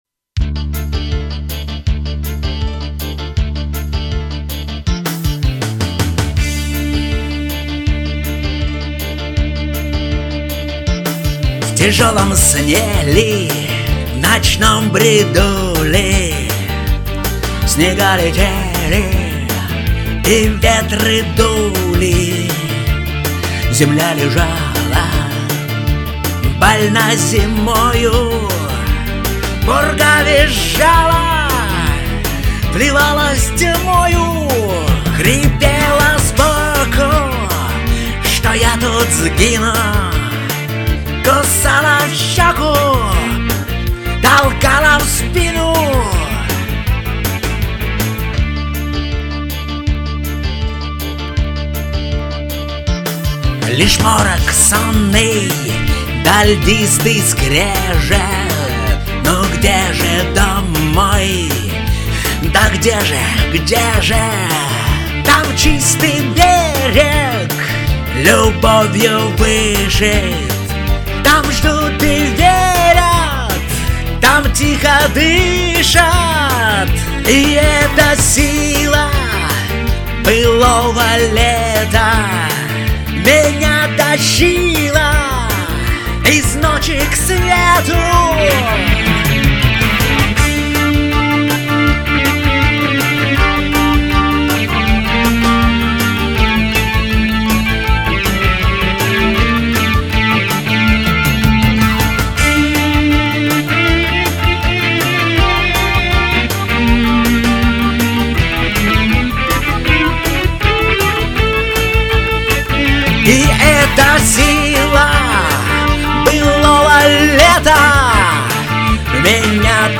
î÷åíü ïîíðàâèëîñü, àòìîñôåðíî.